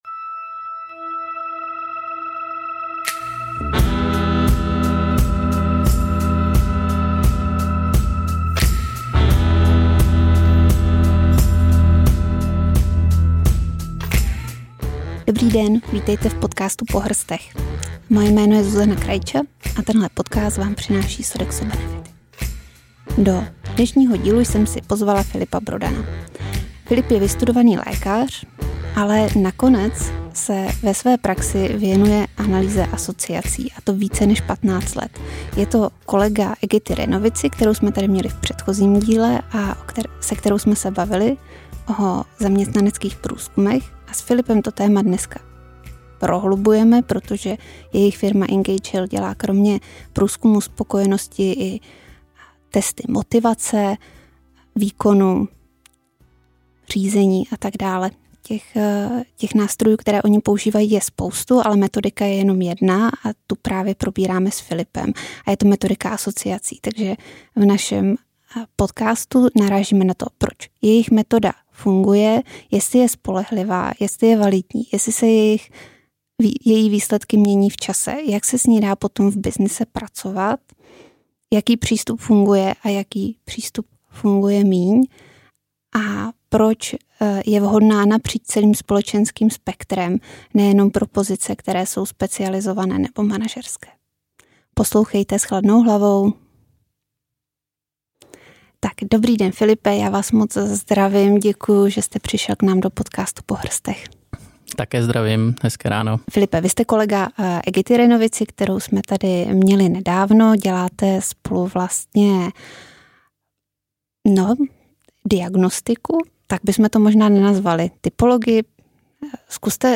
V rozhovoru probíráme metodu asociací, kterou využívají nejen při realizaci firemních průzkumů spokojenosti, ale i při přípravě a vyhodnocení testů zaměřených na motivaci, výkon nebo řízení.